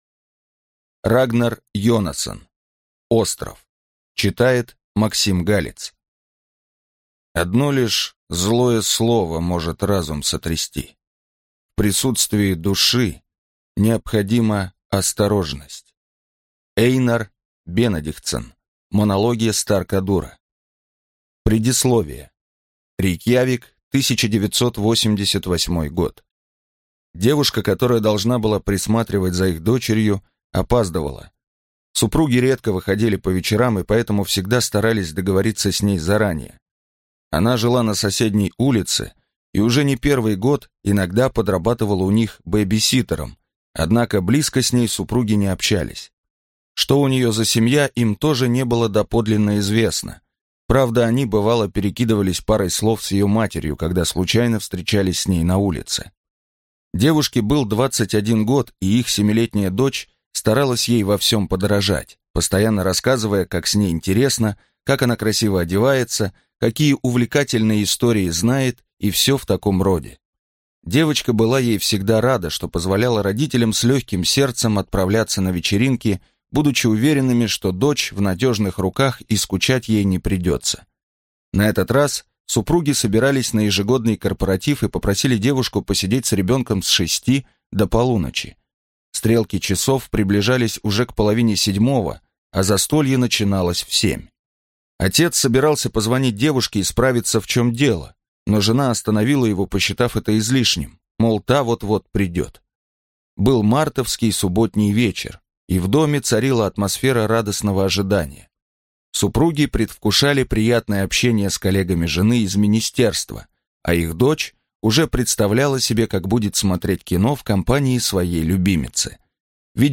Аудиокнига Остров | Библиотека аудиокниг